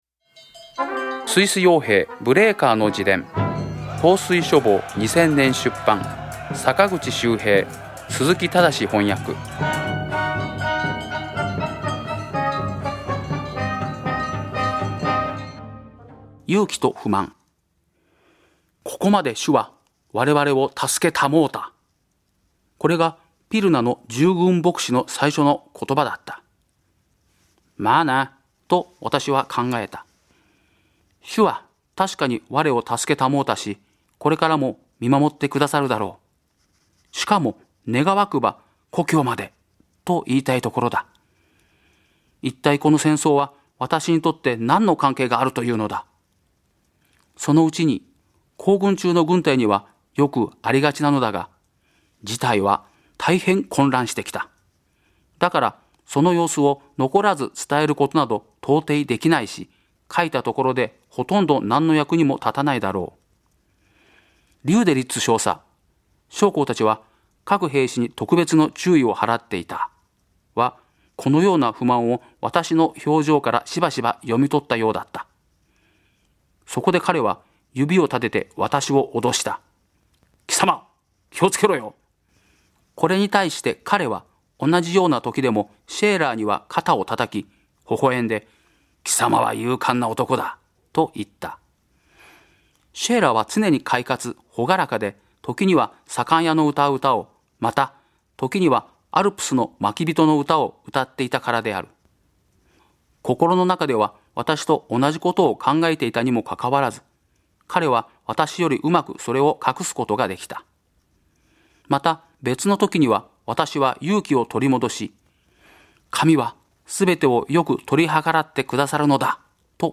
朗読『スイス傭兵ブレーカーの自伝』第56回